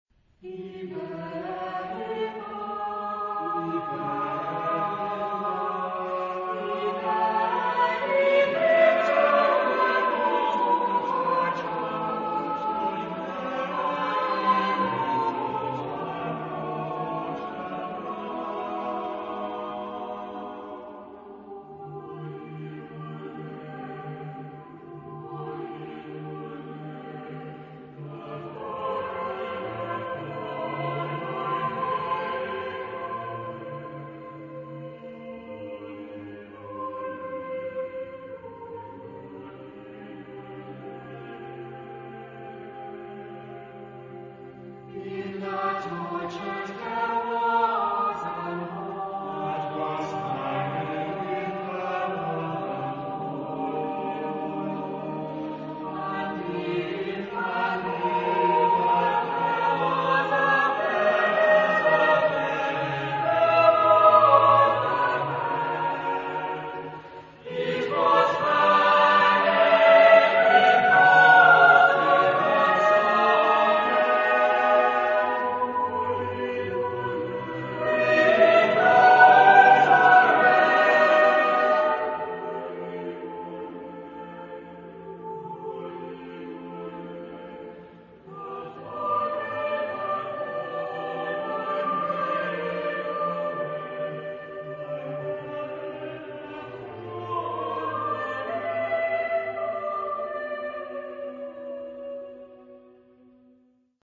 Genre-Style-Forme : Sacré ; Chœur
Type de choeur : SATB + SATB  (8 voix mixtes )
Consultable sous : 20ème Sacré Acappella